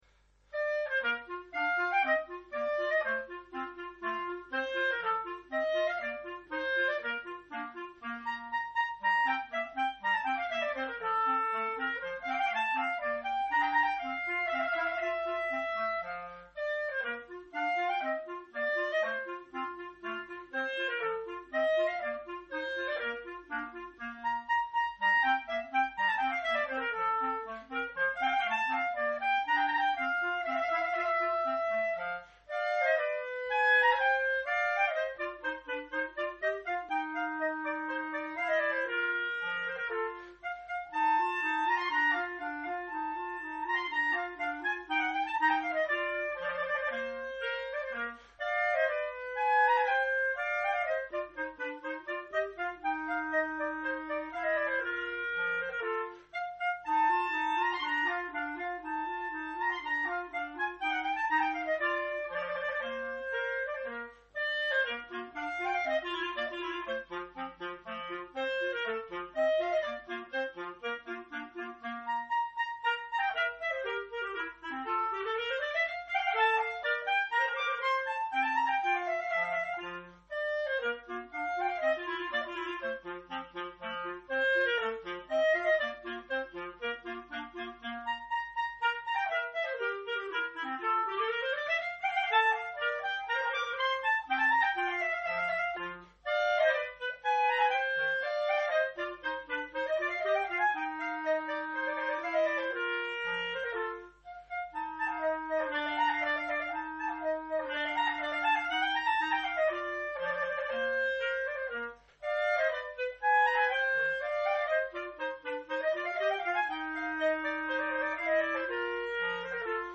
Duo N° 3 opus 5 en ut M.
2e mouvement, Andante con variatione (4'16)